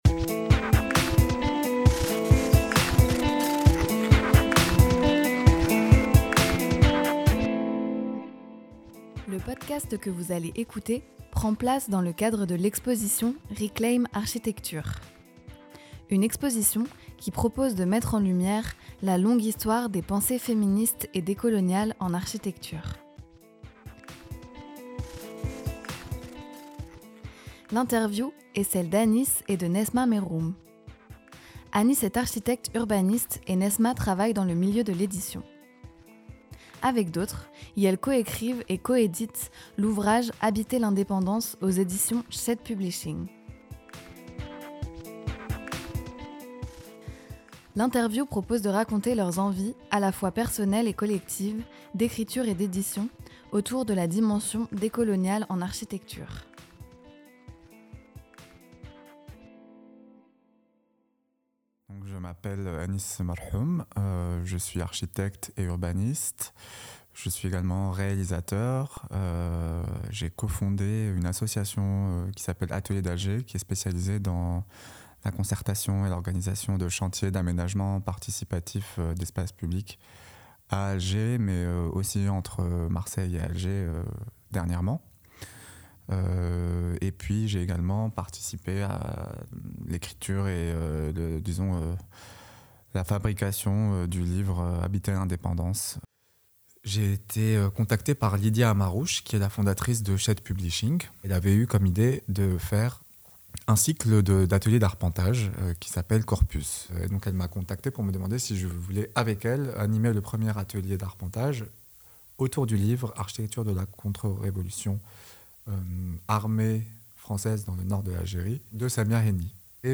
propose un entretien inédit